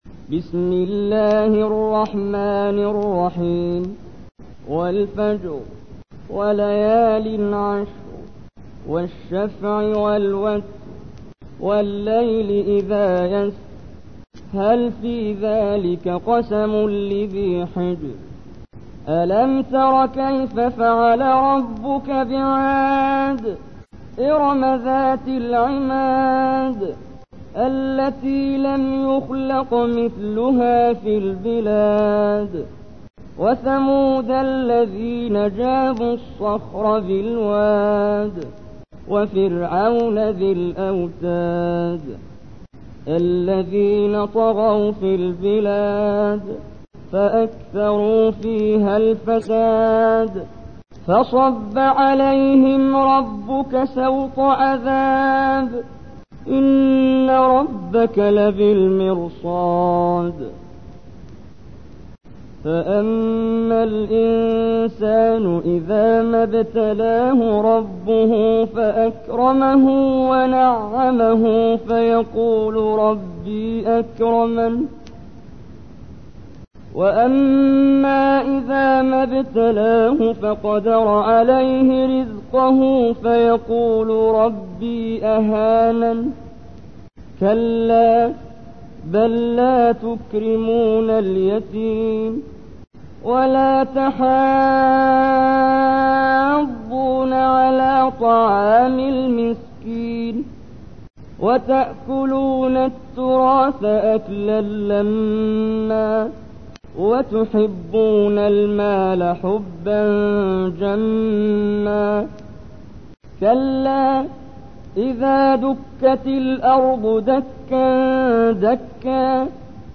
تحميل : 89. سورة الفجر / القارئ محمد جبريل / القرآن الكريم / موقع يا حسين